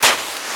High Quality Footsteps
STEPS Sand, Walk 10.wav